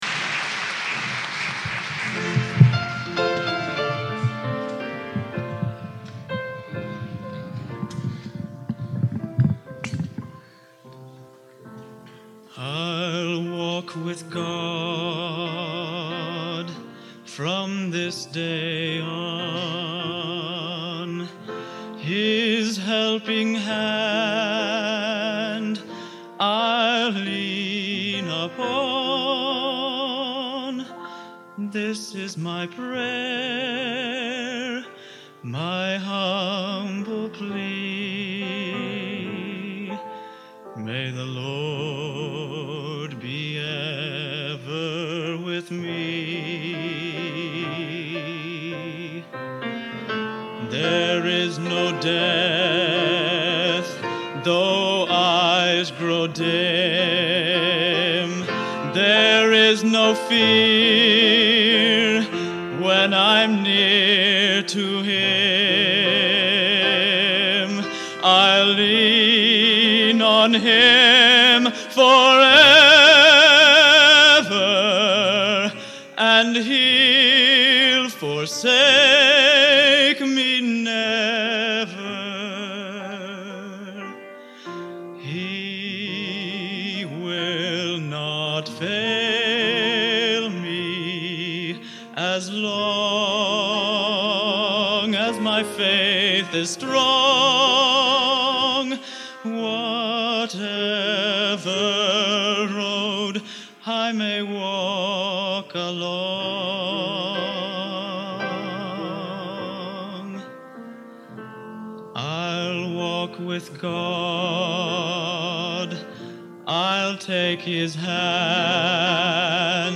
Collection: Broadway Methodist, 1980
Genre: Modern Sacred | Type: